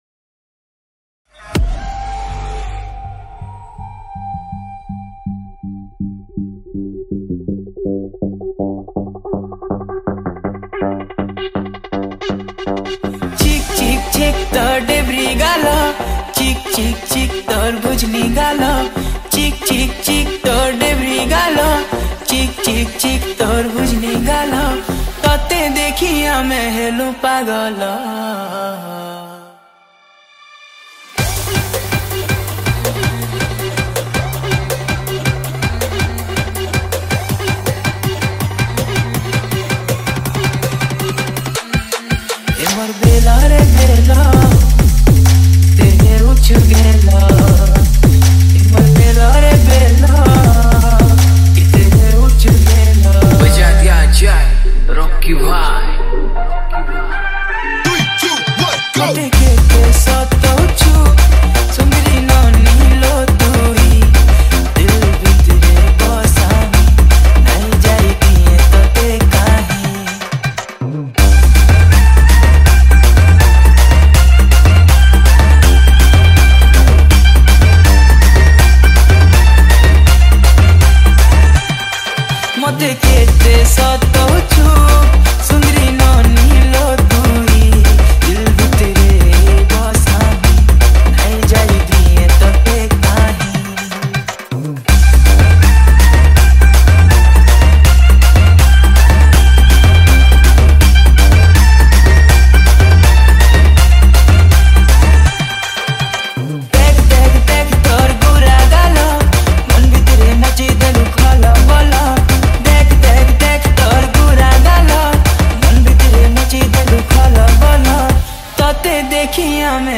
ALL DJ ARTIST REMIX